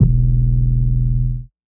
SOUTHSIDE_808_lofi_ufo_E.wav